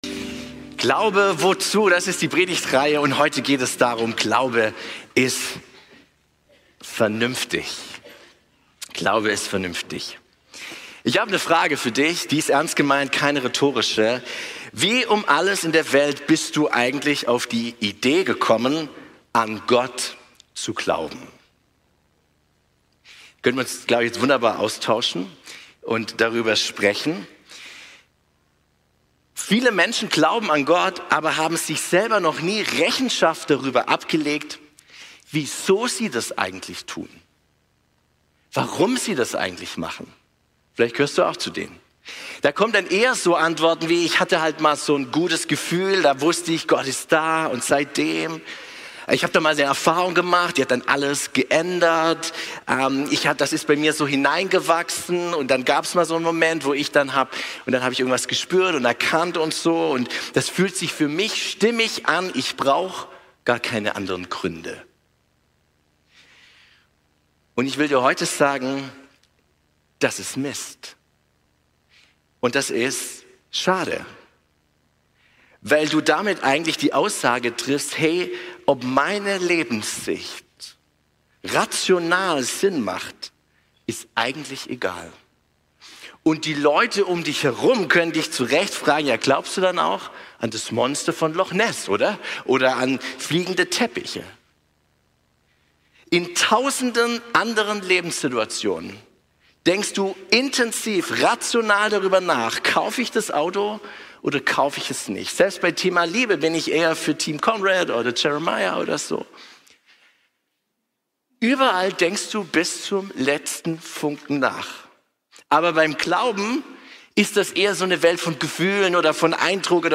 Passage: Hebräer 10, 1-3 Typ: Predigt Glauben ist vernünftig!